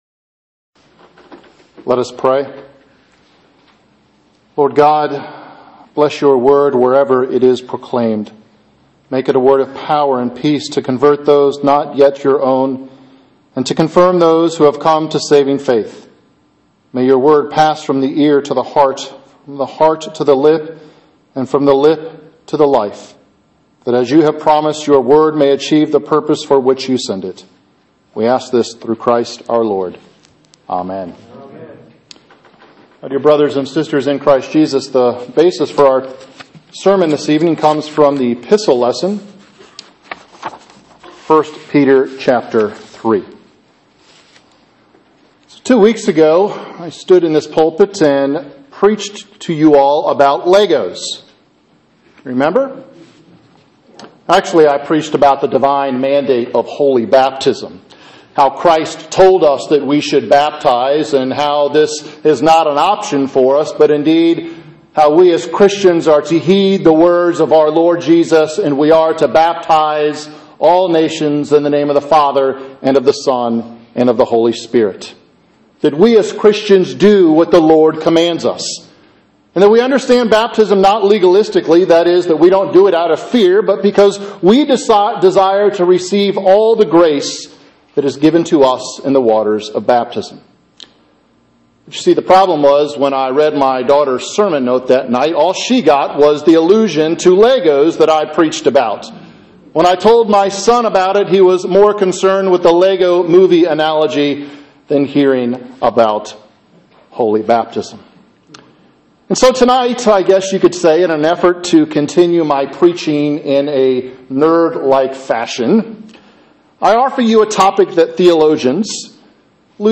Sermon: Lent 3 Midweek 1 Peter 3:18-22